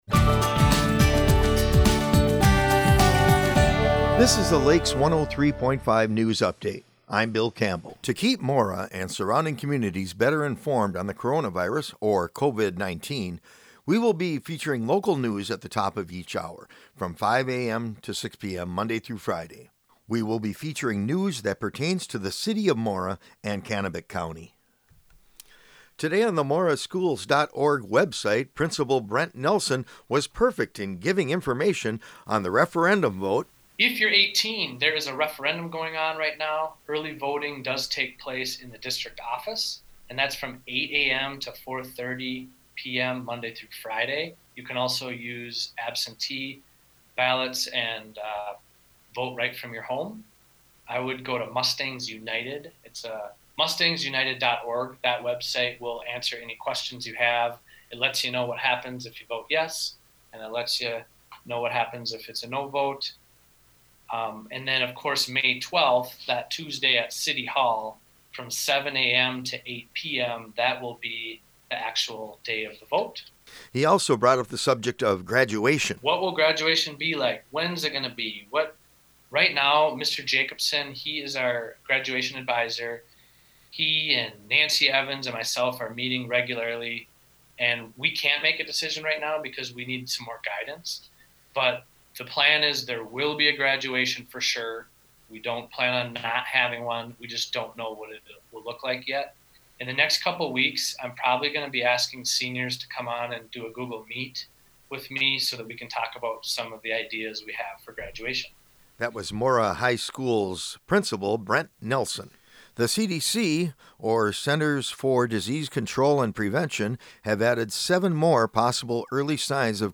This is an archived recording of a feature originally broadcast on Lakes 103.